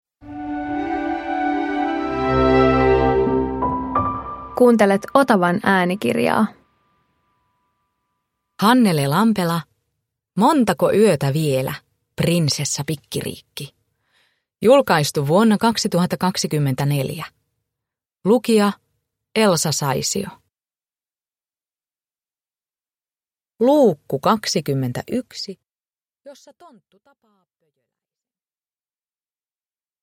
Montako yötä vielä, Prinsessa Pikkiriikki 21 – Ljudbok